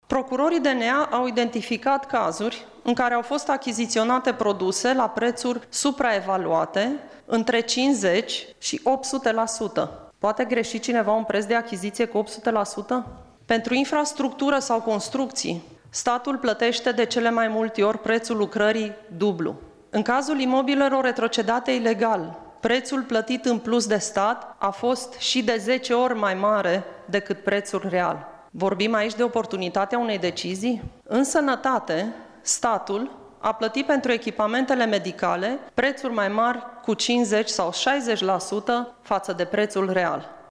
Lupta împotriva corupţiei este esenţială într-un stat modern, dar eforturile în acest sens trebuie susţinute de un cadru legislativ şi instituţional stabil, a afirmat, astăzi, la procurorul şef al DNA, Laura Codruţa Kovesi, la bilanţul instituţiei pe anul trecut.
Şefa procurorilor anticorupţie a mai declarat că atât timp cât planificarea achiziţiilor nu va fi transparentă, bugetul va putea fi folosit pentru achiziţii supraevaluate şi plăţi pentru lucrări neefectuate: